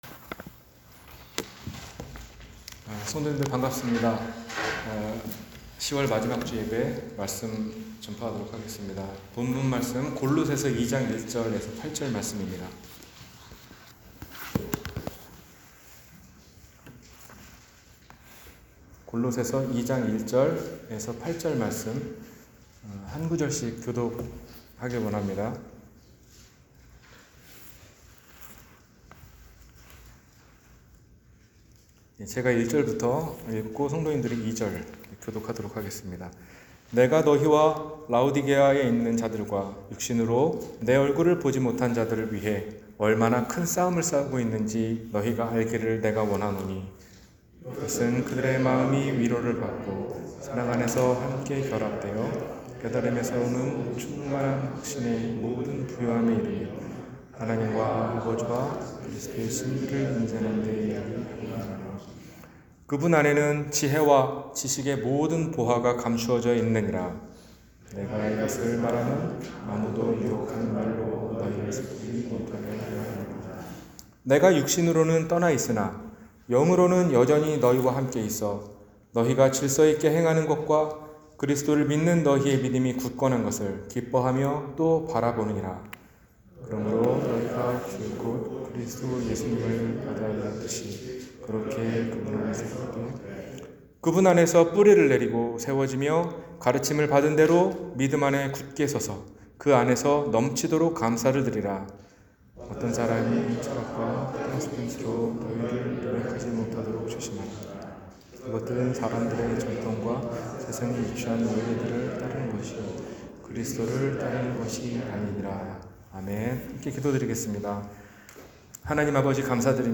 내 안에 계신 그리스도 – 주일예배